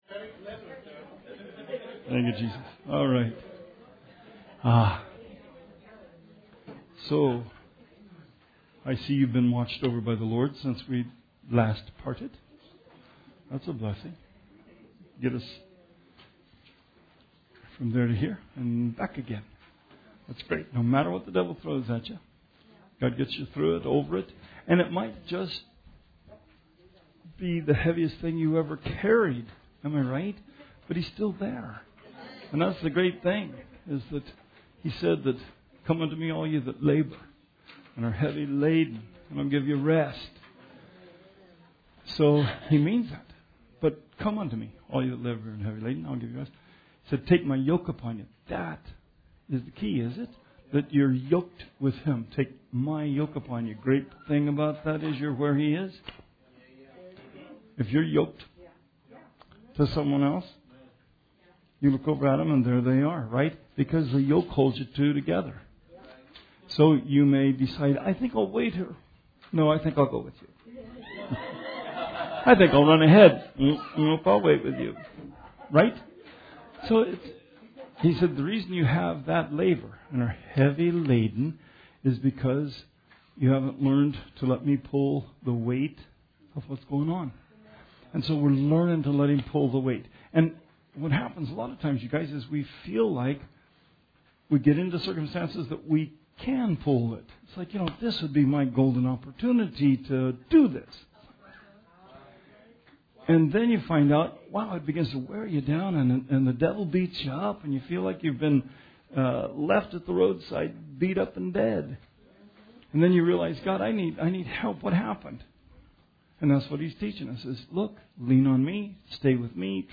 Bible Study 5/30/18